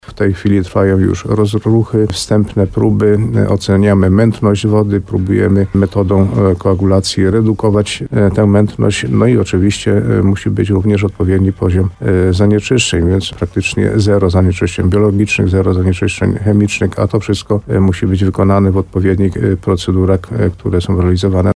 Jak powiedział w programie Słowo za Słowo w radiu RDN Nowy Sącz wójt Gminy Ropa Karoli Górski, teraz instalacja przechodzi ostatnie testy.